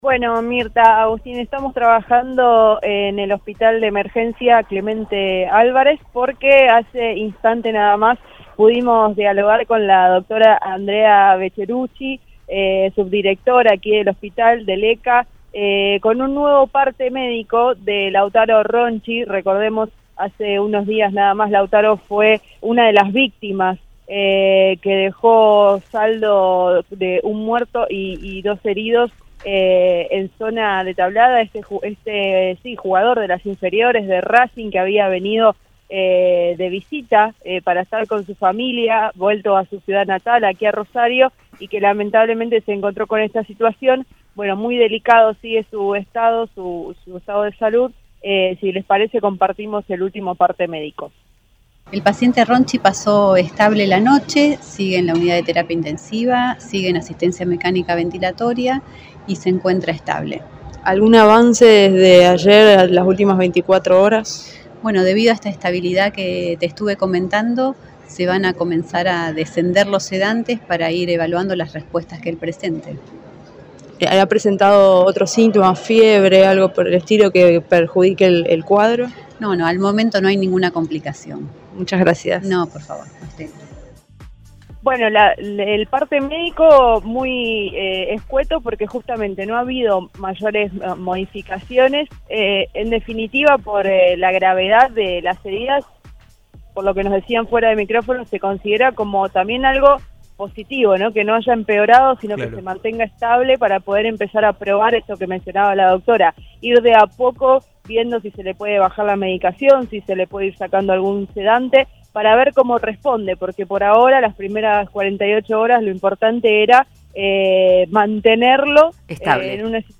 En contacto con el móvil de Cadena 3 Rosario, en Una mañana para todos,